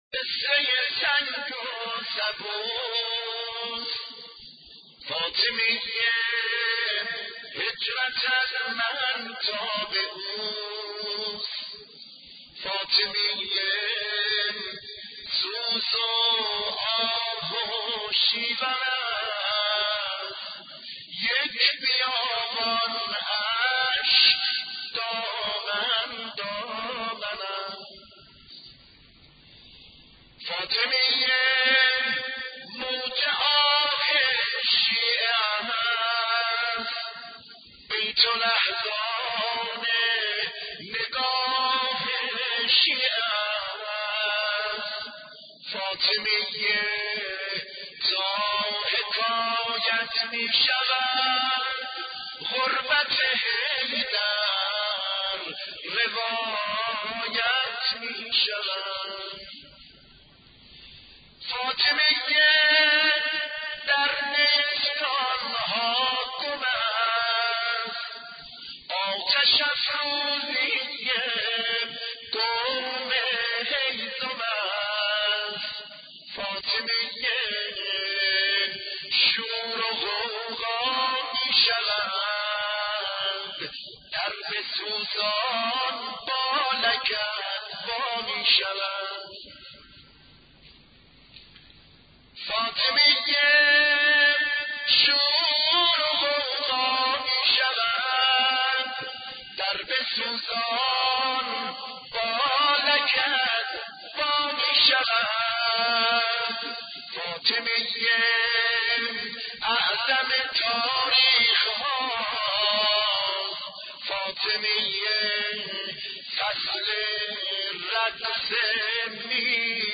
مراسم روضه خوانی (ترکی) به مناسبت شهادت حضرت زهرا(س)